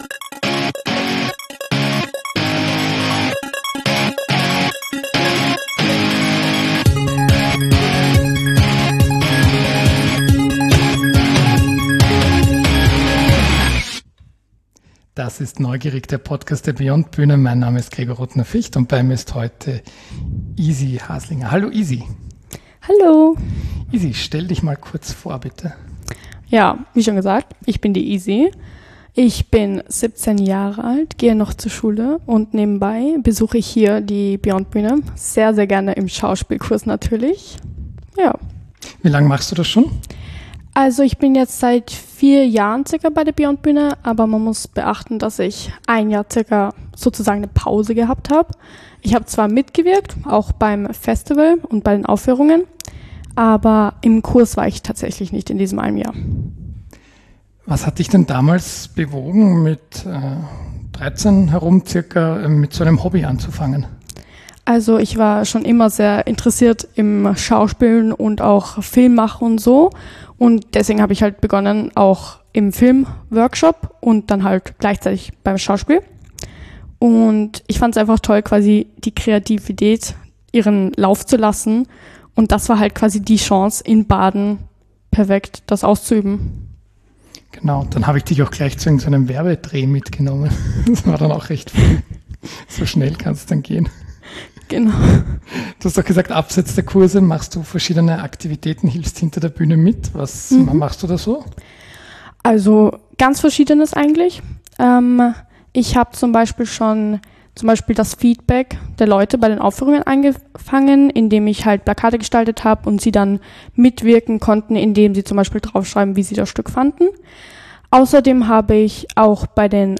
Interview mit unserem langjährigen Mitglied